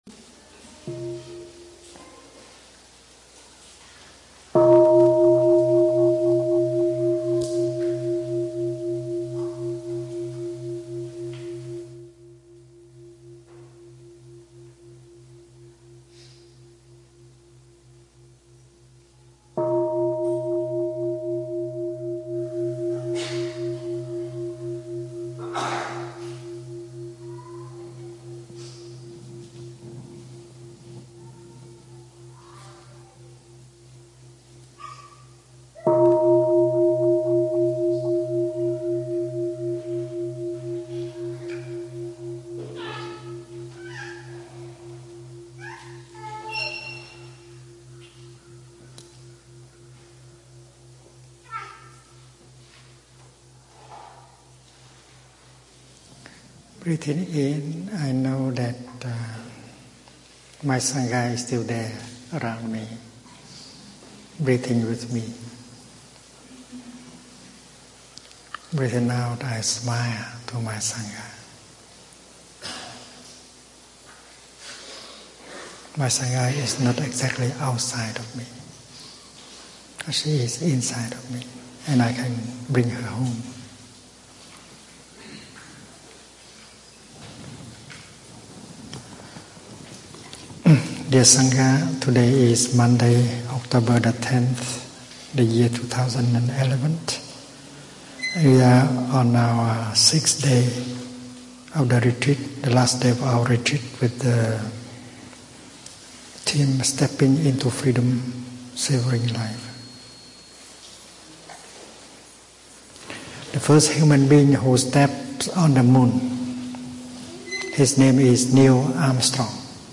The sangha is on the North American Tour and this is the final dharma talk for the Stepping Into Freedom, Savoring Life Retreat.